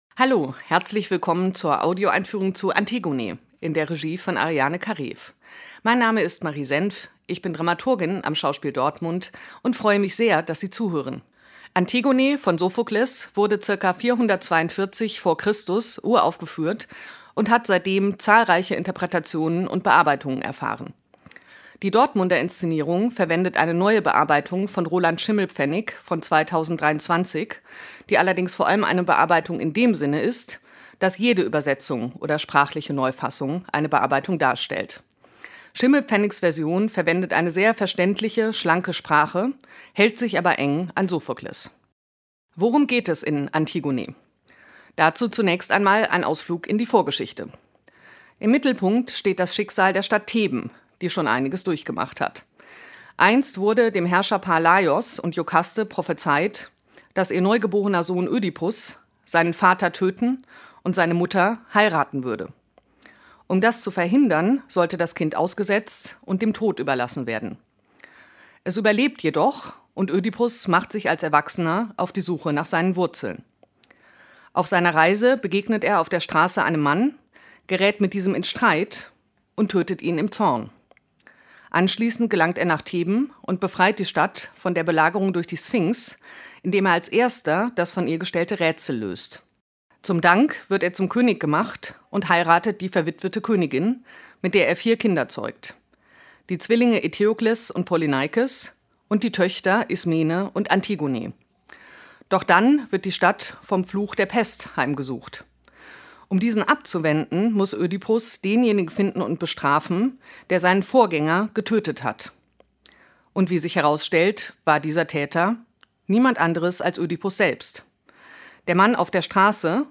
tdo_einfuehrung_antigone.mp3